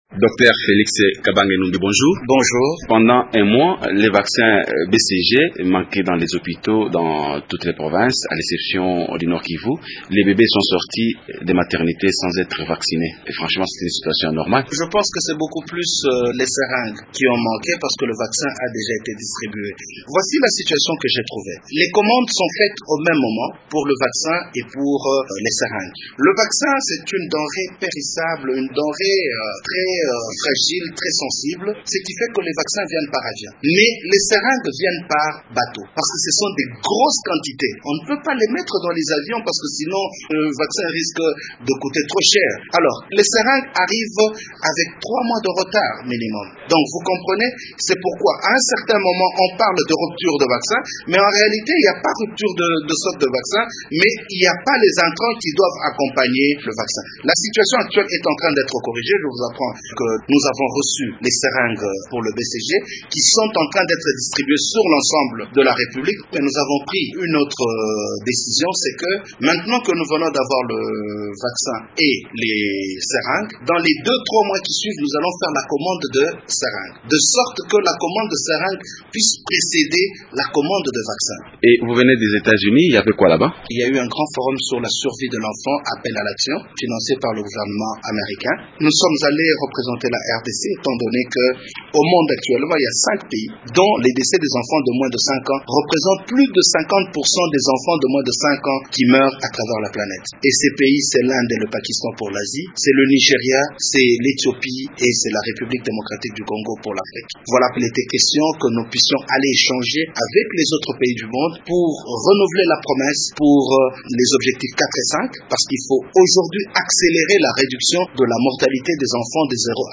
Dr Félix Kabange explique les efforts que fournit le gouvernement pour redresser cette situation.